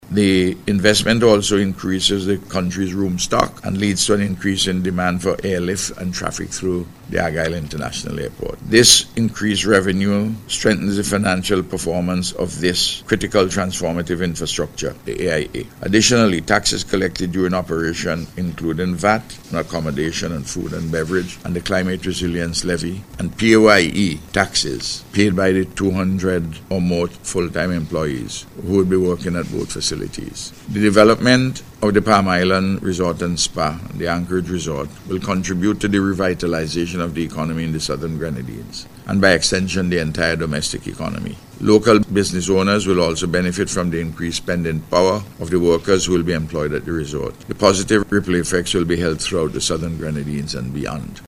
He made this statement during Monday’s signing ceremony for